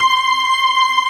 Index of /90_sSampleCDs/AKAI S6000 CD-ROM - Volume 1/VOCAL_ORGAN/POWER_ORGAN
P-ORG2  C6-S.WAV